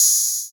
Closed Hats
HiHat (4).wav